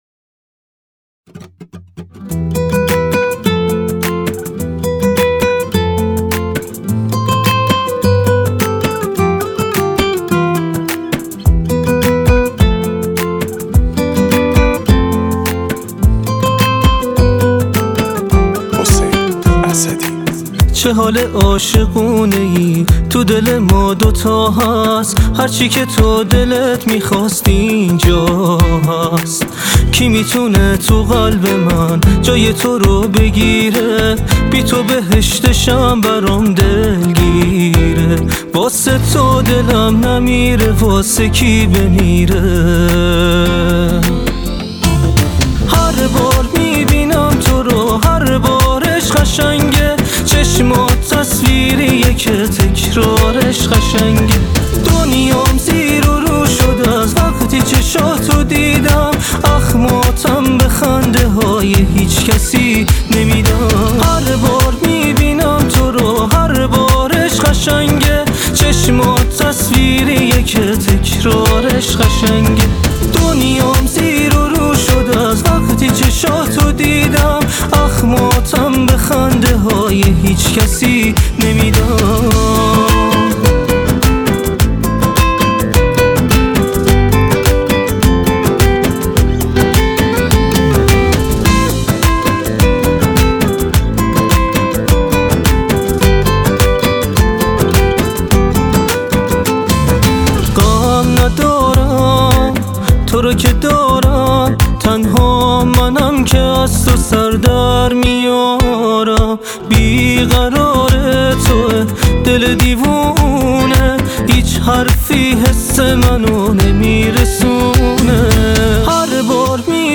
خوانندگی
پاپ